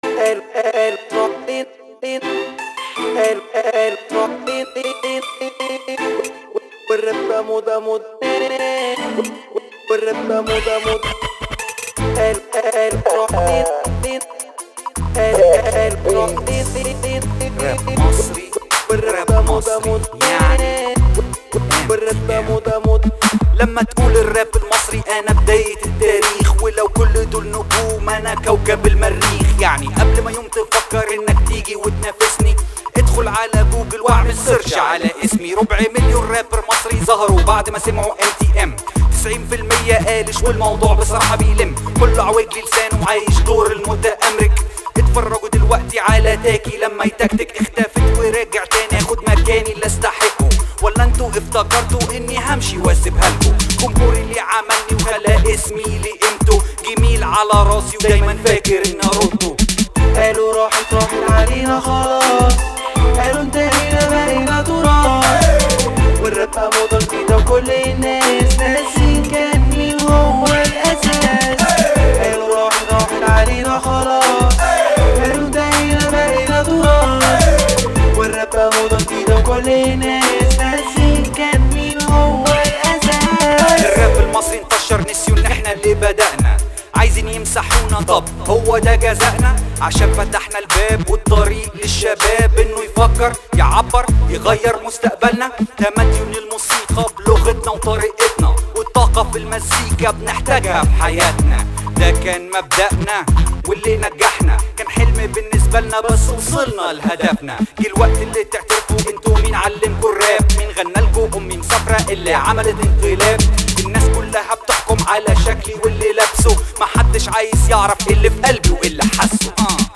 راب مصرى محدش يقدر يتحداه